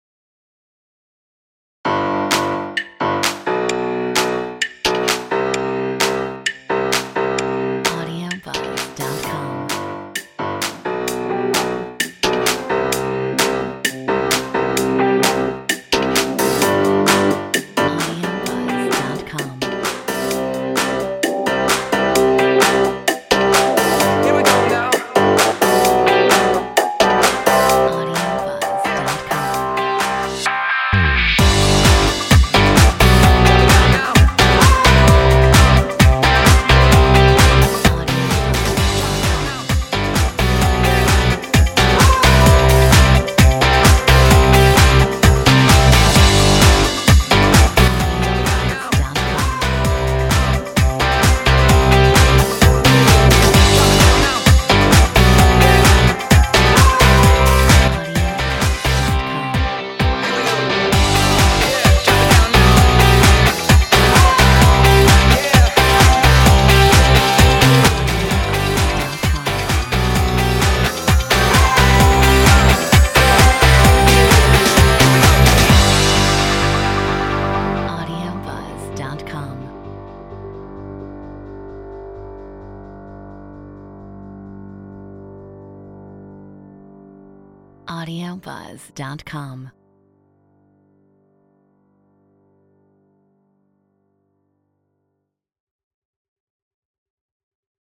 Metronome 130